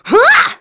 Aselica-Vox_Attack4.wav